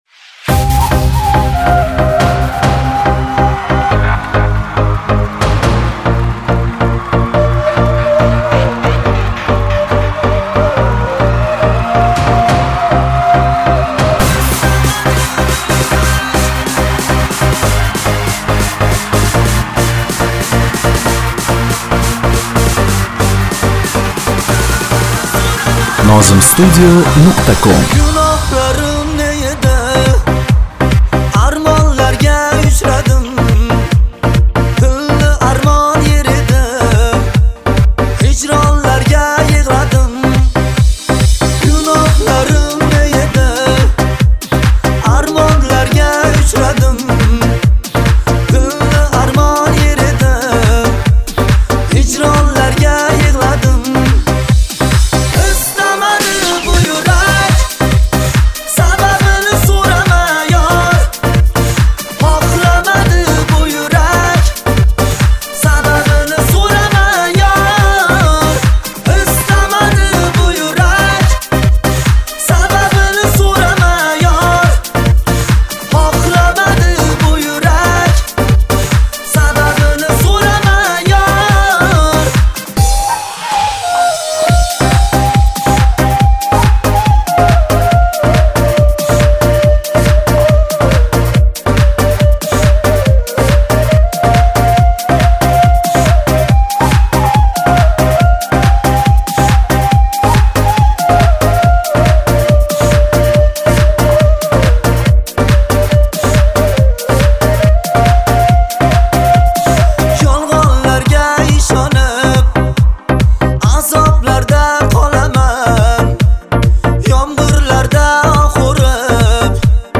minus скачать мр3 2022